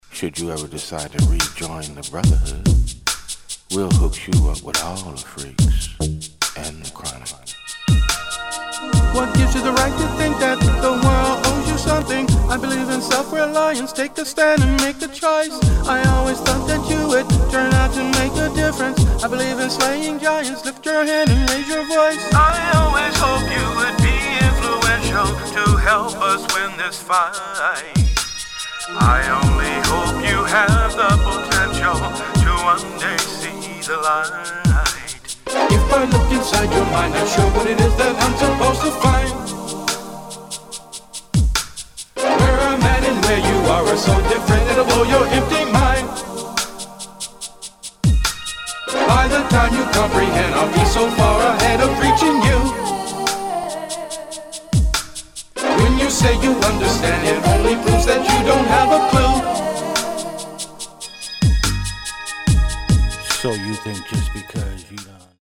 [ SOUL / HIP HOP / FUNK ]